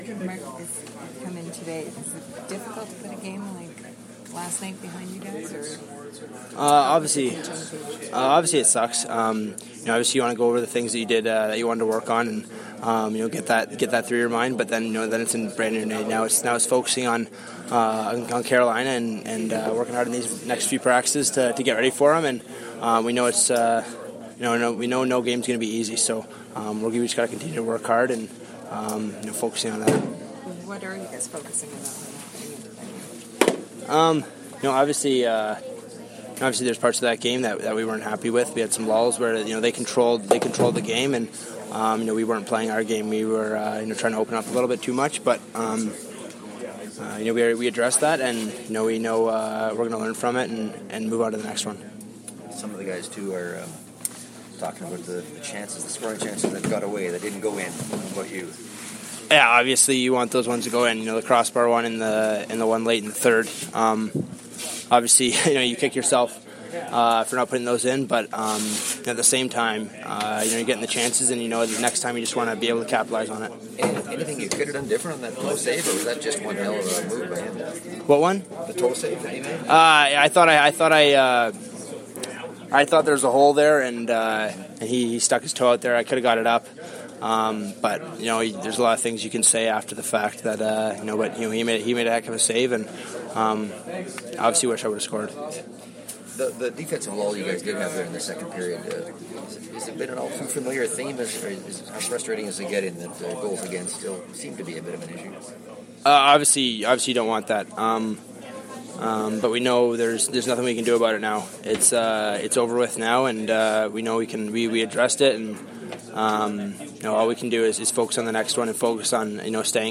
More to come following skate including audio from the Jets room.